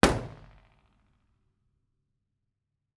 IR_EigenmikePL001F1_processed.wav